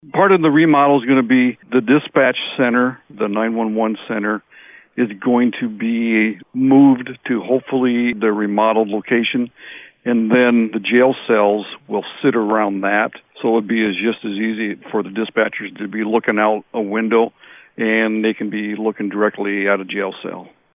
The sheriff tells us what they’d like to do to fix that problem.